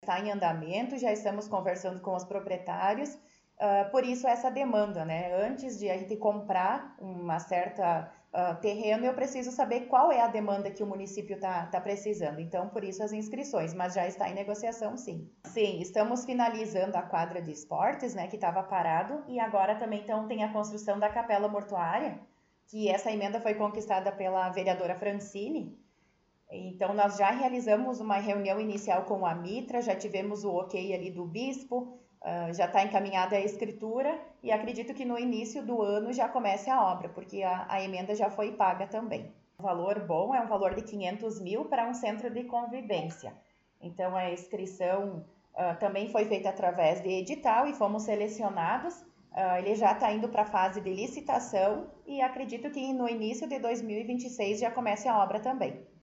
Secretária Municipal de Planejamento concedeu entrevista